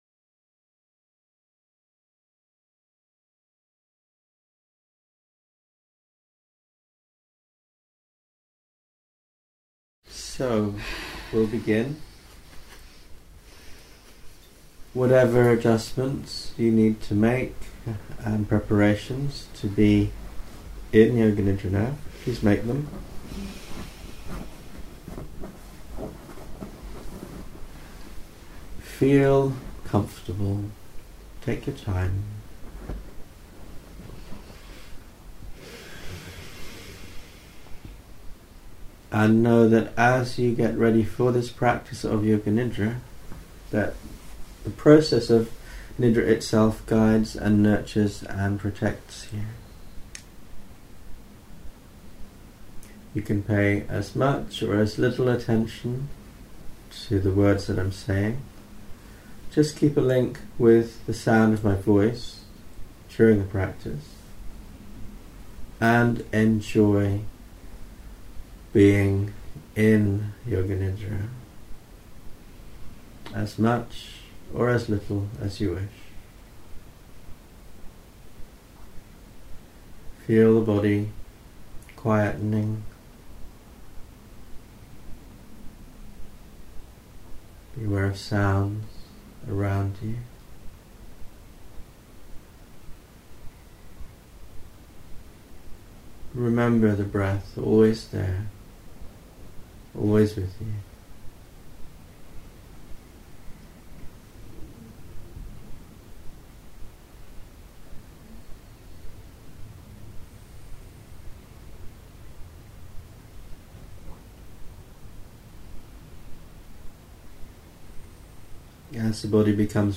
32 Trance Garden Vocal Mode: Spoken word